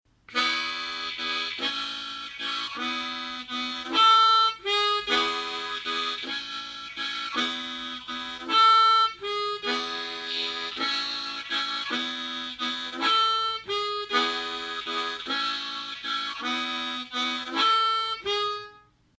El ejercicio 6 es muy útil para aprender a tocar acordes y aislar notas individuales en la misma rutina musical. Tiempo: 50 bpm, máximo 70 bpm. 4/4 – Harmonica en tonalidad: A
AHCOD - Audio_Exercise 6.mp3